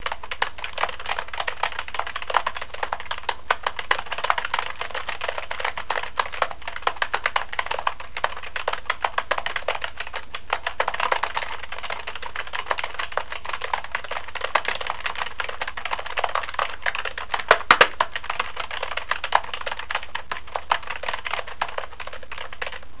typing2
fast keyboard typing sound effect free sound royalty free Memes